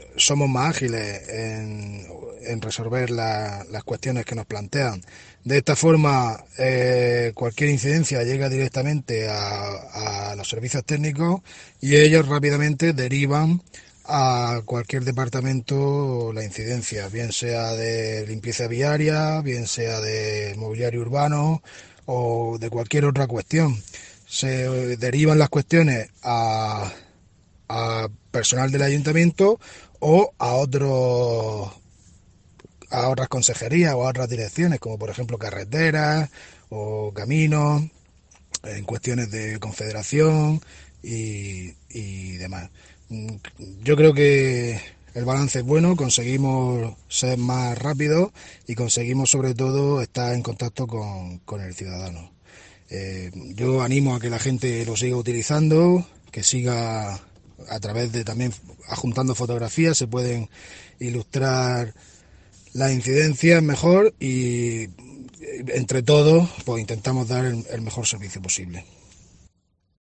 César Gázquez, concejal Ayuntamiento de Puerto Lumbreras